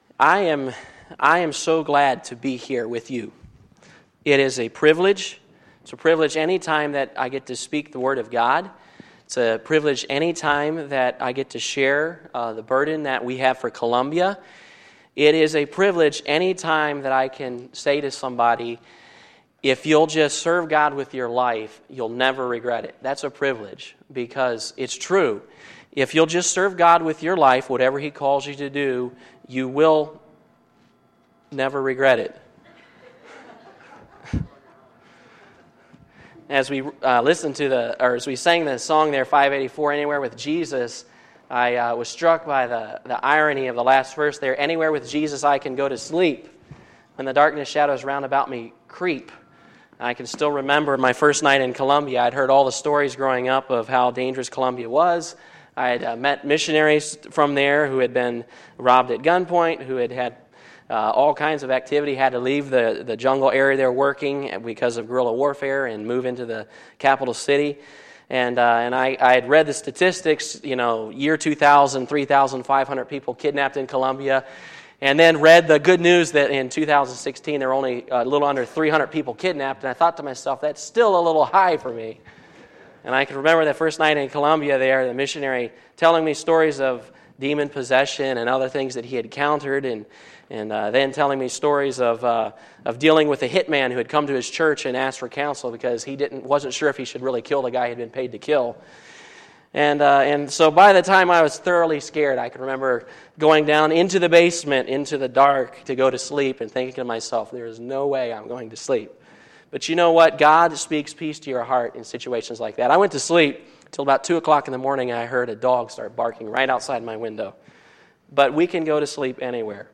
Sunday, September 22, 2019 – Missions Conference Session 3
Sermons